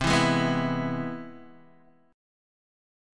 C+M7 chord